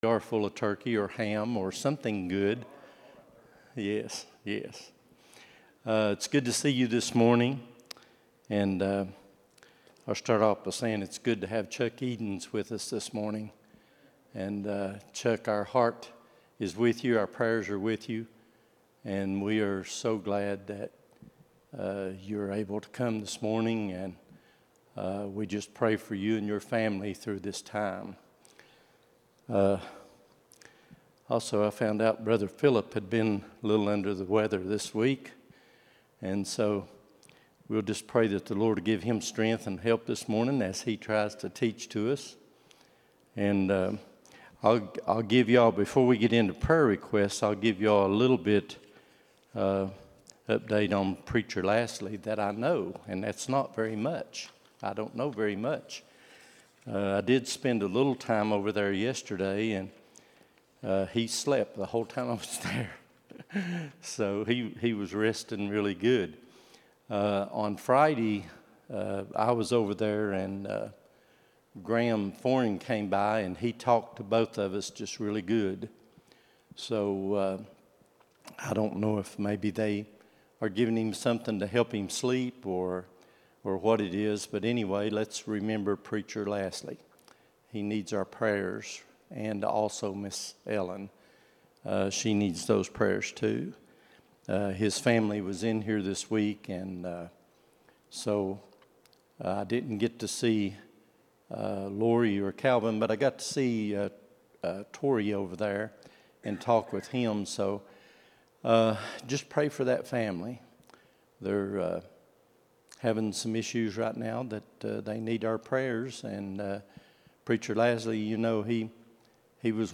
12-01-24 Sunday School | Buffalo Ridge Baptist Church